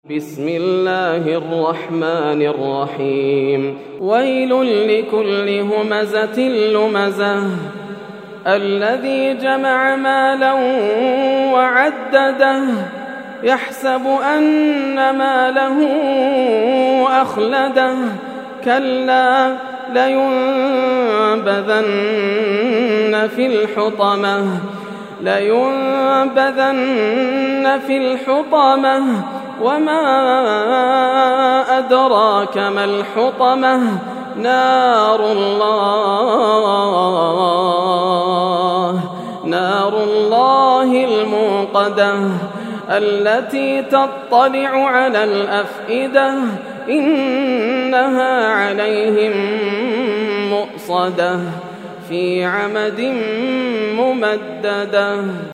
سورة الهمزة > السور المكتملة > رمضان 1431هـ > التراويح - تلاوات ياسر الدوسري